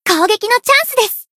贡献 ） 分类:彩奈 分类:蔚蓝档案语音 协议:Copyright 您不可以覆盖此文件。
BA_V_Aris_Battle_Shout_3.ogg